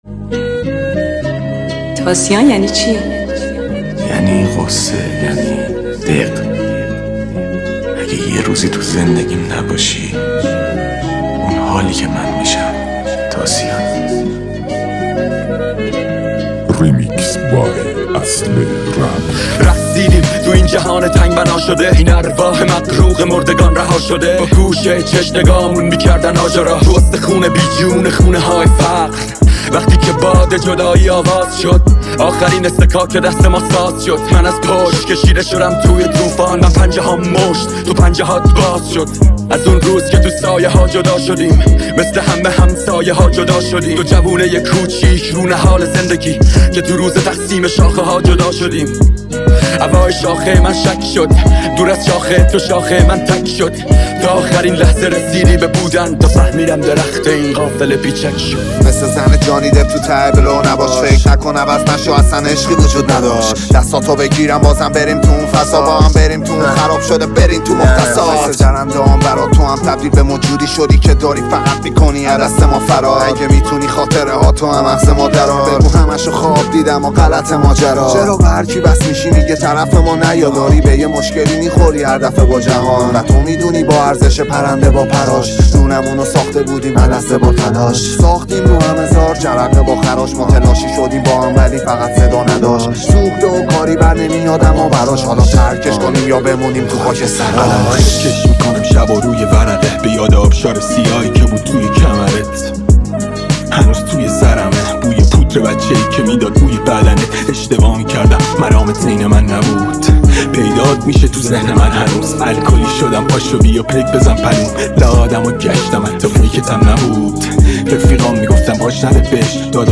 ژانر: رپ